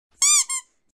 rubber-duck-sound-effect-for-editing.mp3